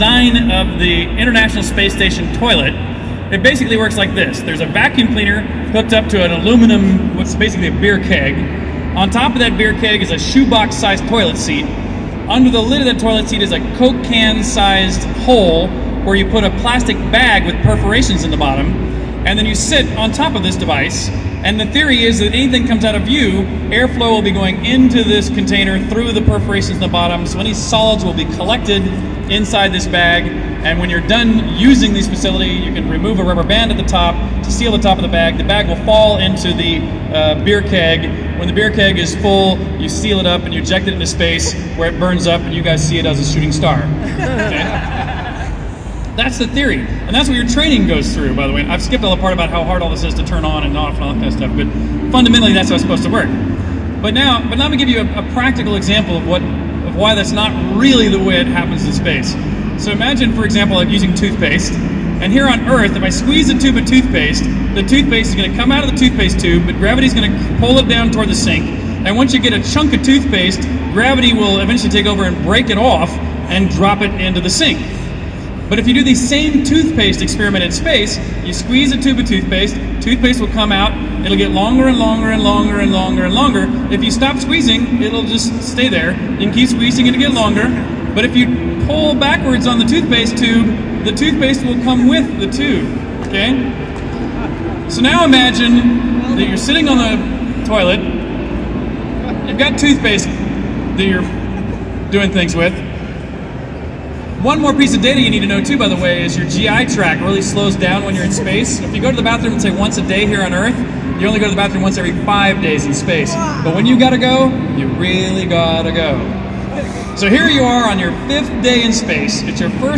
How to go to the toilet in space. Games entrepeneur and private space explorer Richard Garriott explains.